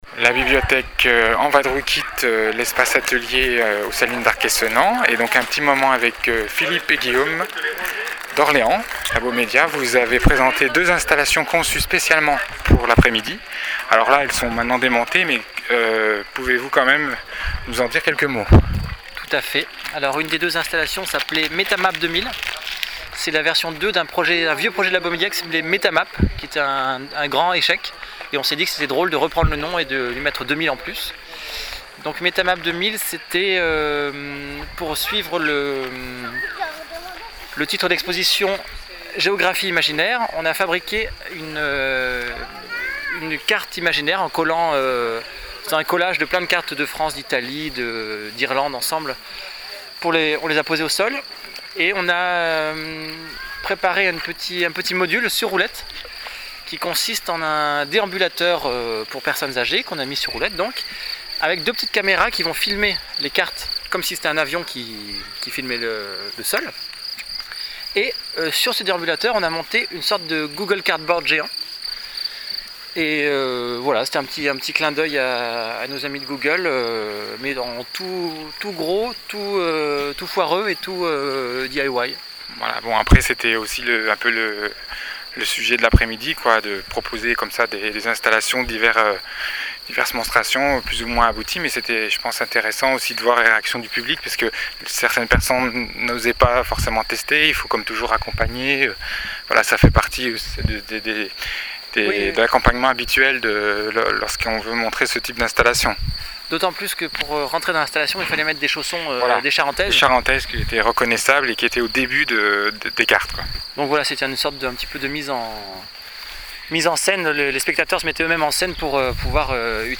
Lieu : Saline Royale, Arc-et-Senans (Doubs)
La bibliothèque en vadrouille  a pu mettre a profit ce déplacement en terres arc-et-senanaises en réalisant sept entretiens avec plusieurs des invités de l’Espace La Fabrique installé dans un bâtiment de la Saline.